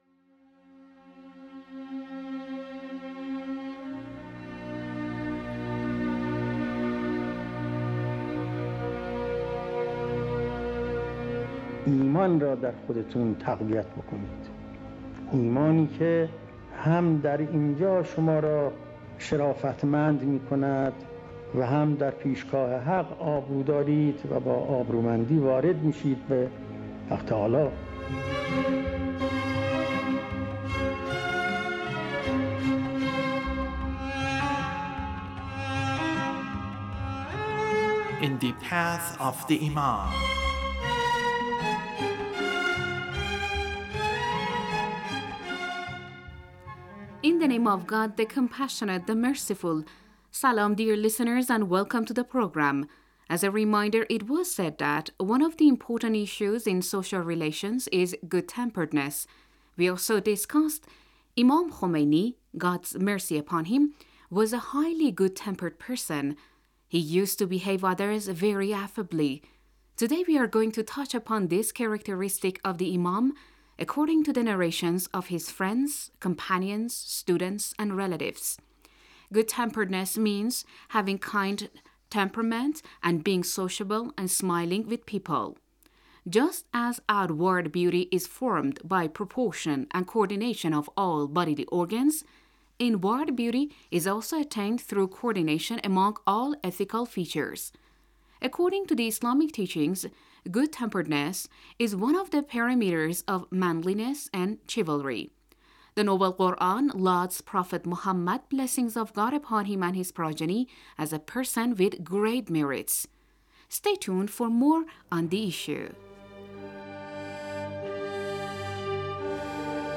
The radio series sheds light on the life of the Founder of the Islamic Republic of Iran, Imam Khomeini (God bless his soul) and his struggles against the Shah's despotic regime aimed at bringing about independence and freedom for the Iranian nation.